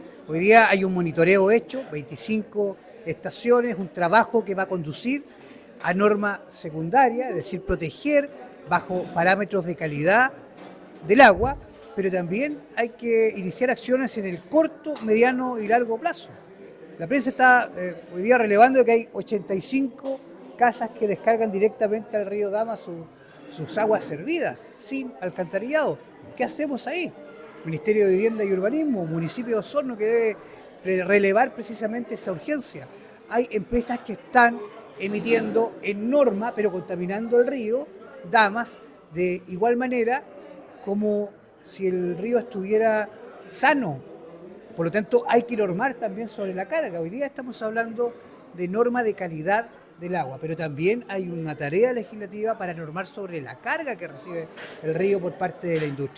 Por otra parte, el Presidente de la Comisión de Medioambiente y Cambio Climático del Consejo Regional Francisco Reyes, enfatizó en que se deben tomar medidas para evitar la contaminación en el río, pues según el estudio se identificó que 85 viviendas en Osorno descargan directamente sus aguas servidas al río sin tratamiento, lo que aumenta la contaminación por coliformes fecales.